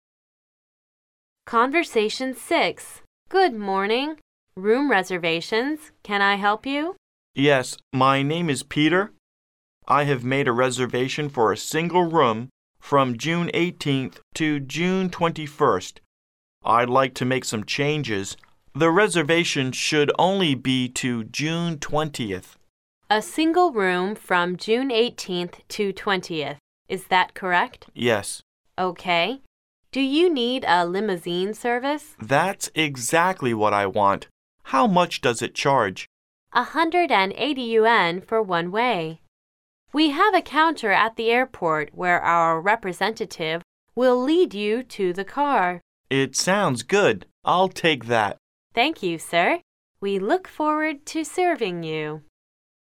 Conversation 6